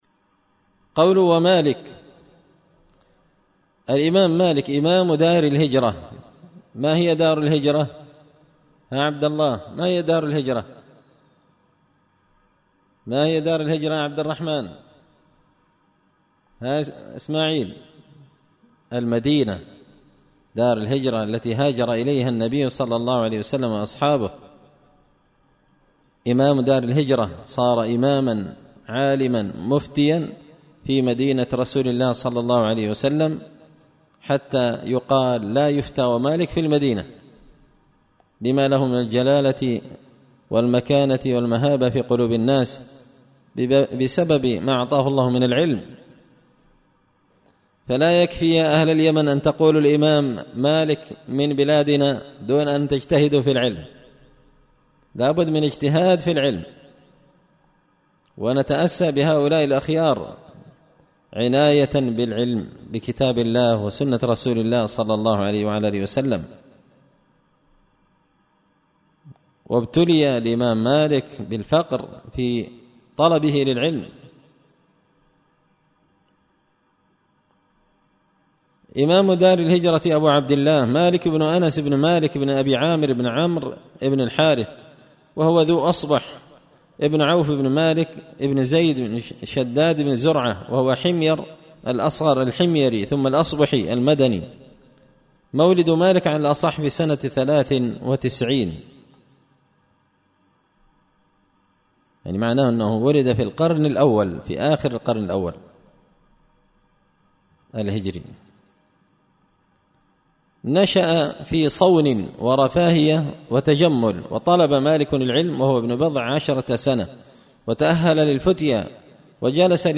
شرح لامية شيخ الإسلام ابن تيمية رحمه الله _الدرس 48